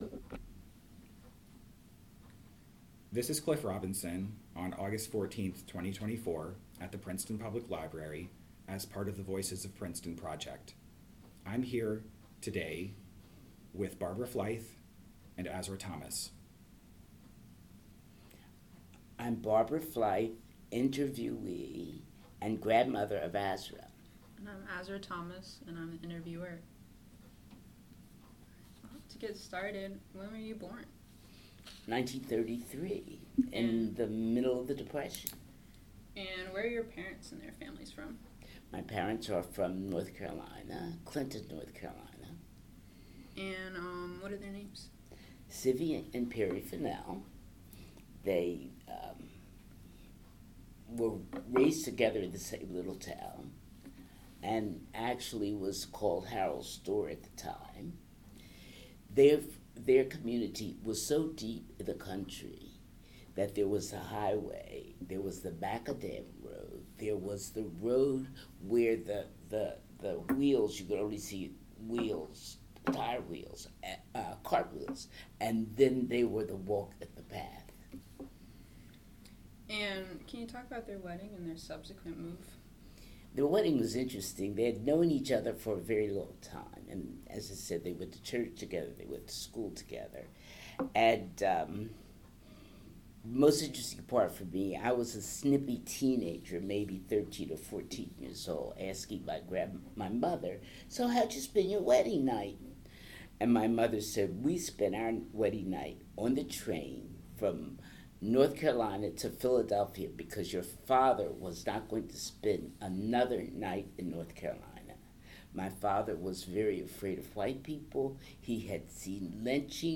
Oral History
Location Princeton Public Library